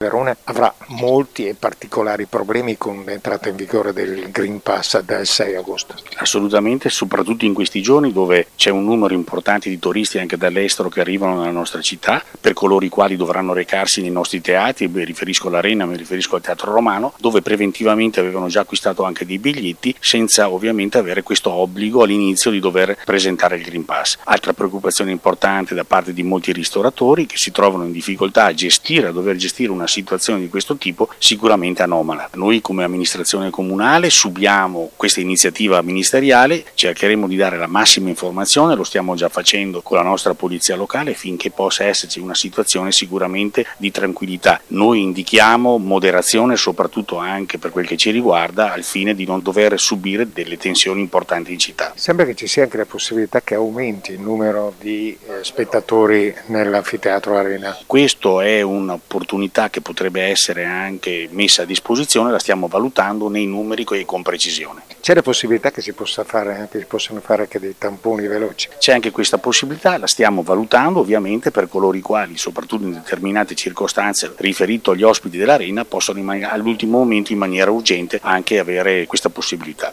Assessore-alla-Sicurezza-del-comune-di-Verona-Marco-Padovani-sul-Green-Pass.mp3